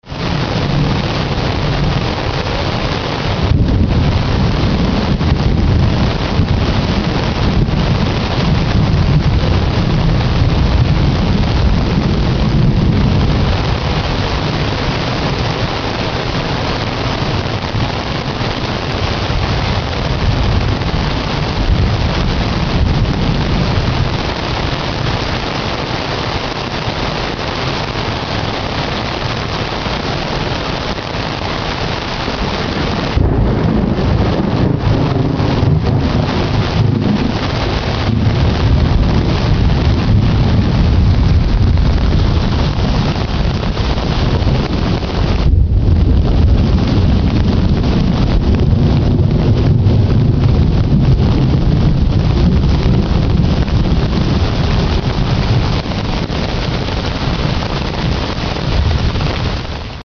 For the next hour or so, we were treated to teeming rain, constant lightning flashes and booming thunder. It was one of the most violent thunderstroms I've ever witnessed!
thunder.mp3